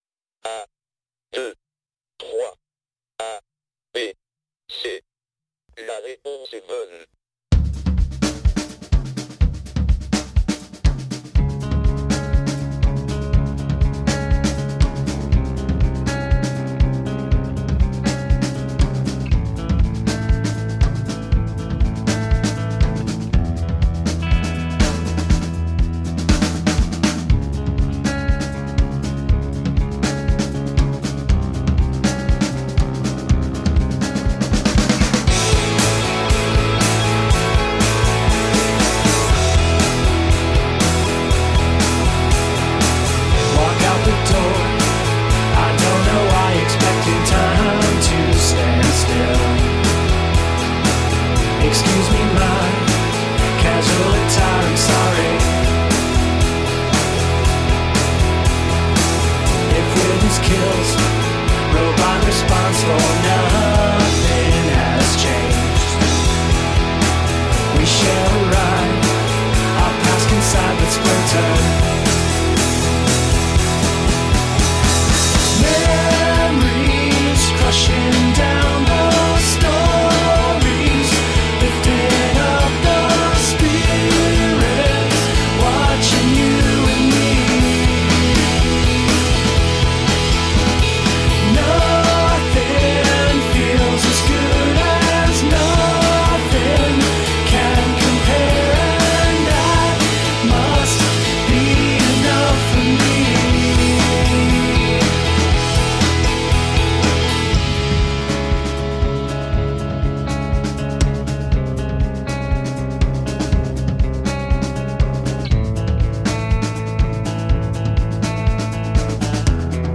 Sophisticated rock with emo undercurrents.